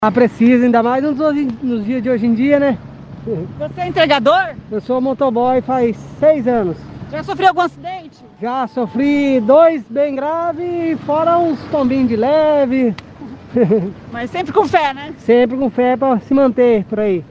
Os veículos que passam pela Avenida Tiradentes, em frente à Catedral, estão recebendo a benção do santo protetor dos viajantes.